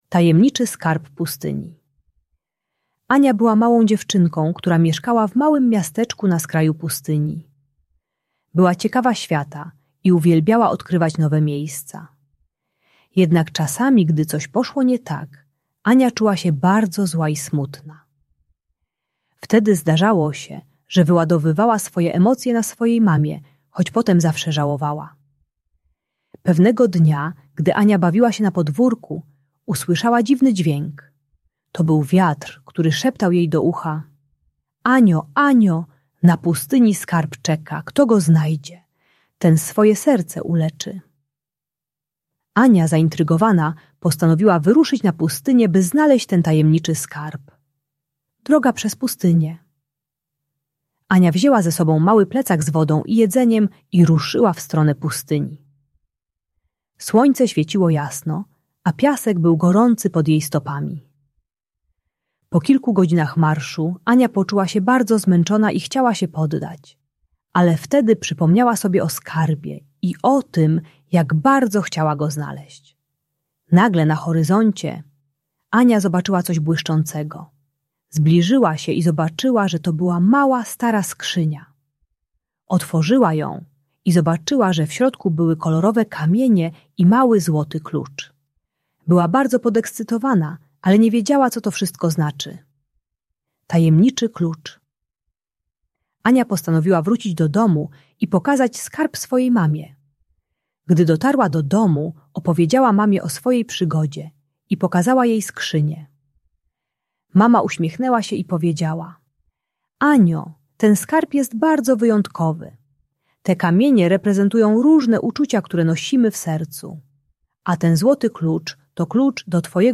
Uczy techniki kolorowych kamieni do nazywania emocji oraz głębokiego oddychania zamiast krzyku czy bicia. Audiobajka o radzeniu sobie ze złością wobec rodziców.